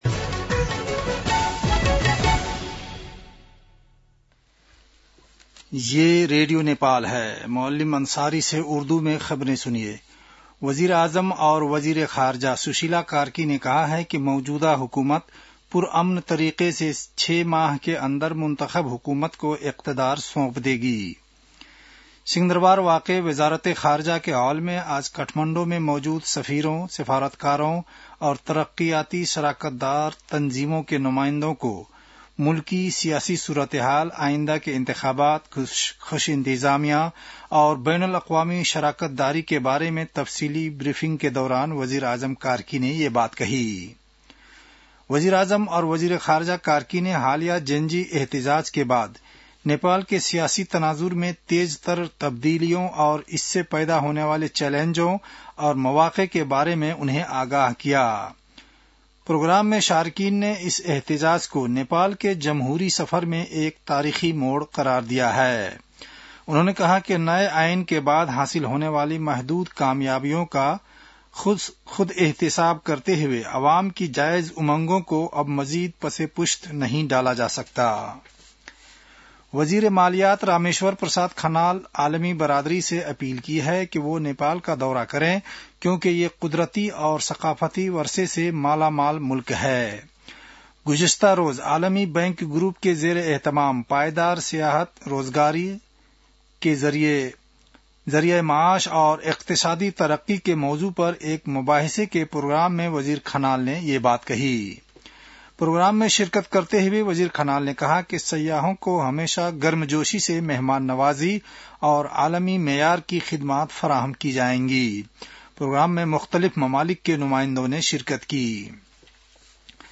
उर्दु भाषामा समाचार : ३१ असोज , २०८२
Urdu-news-6-31.mp3